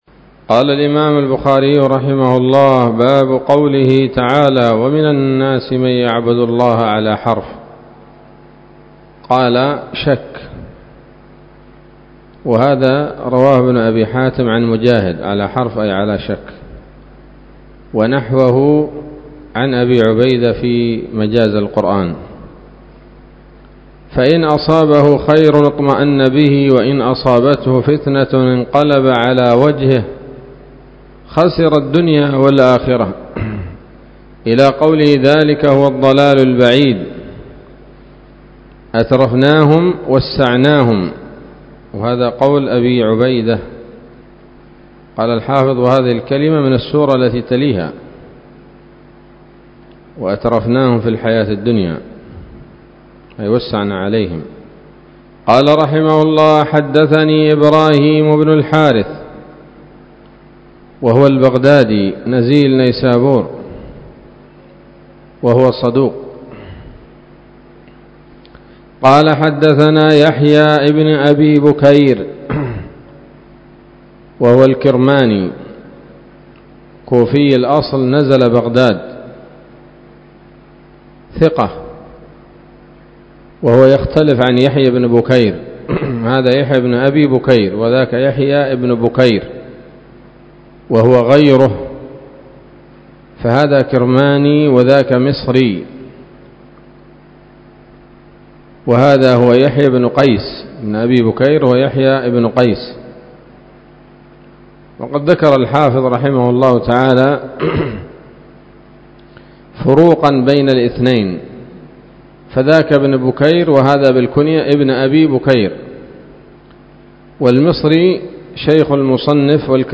الدرس السادس والسبعون بعد المائة من كتاب التفسير من صحيح الإمام البخاري